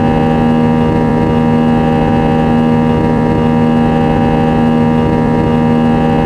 cfm-buzz.wav